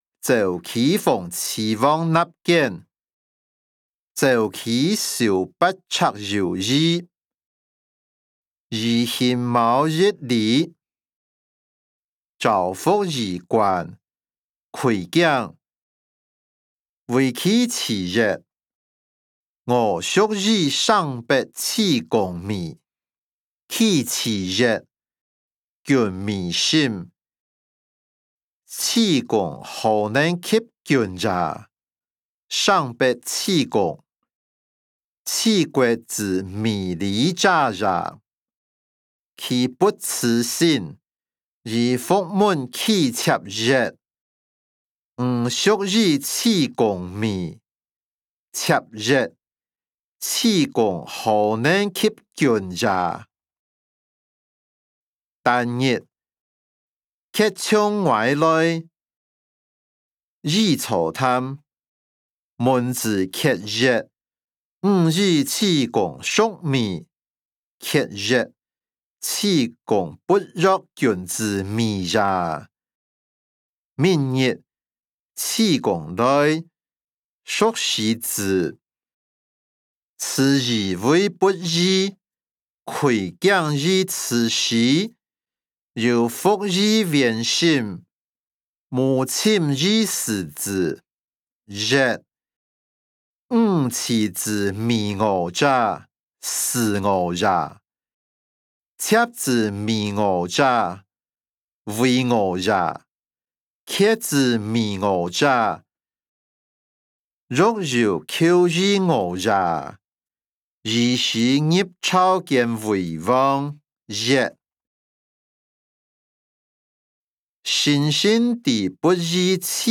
歷代散文-鄒忌諷齊王納諫音檔(饒平腔)